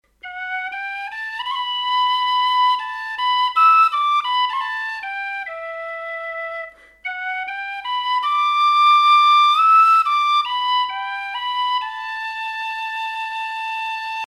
Die Whistles klingen mittellaut und haben einen sehr hübschen, etwas "breathy"-Sound.